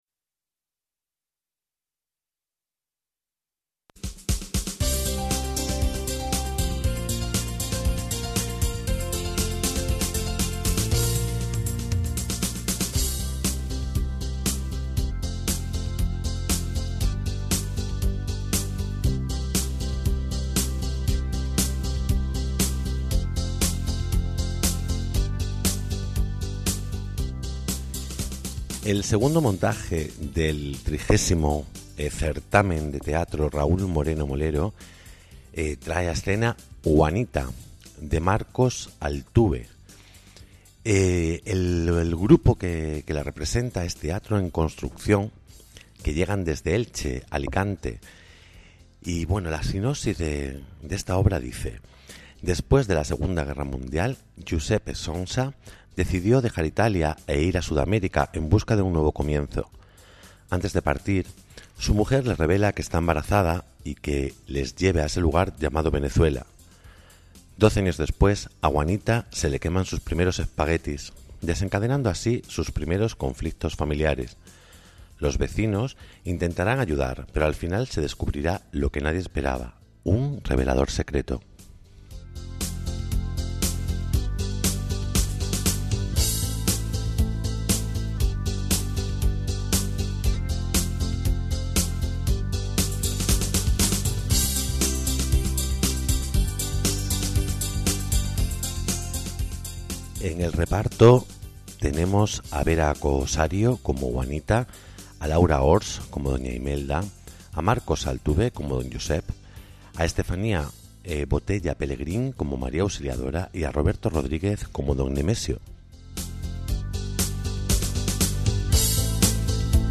También entrevistamos